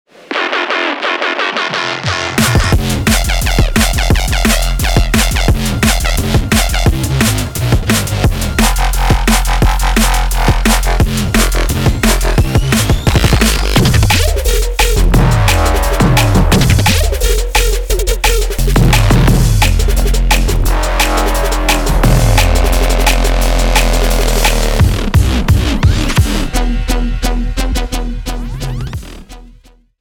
viscous drum and bass for serum
Viscous for Serum (Drum & Bass Serum Presets)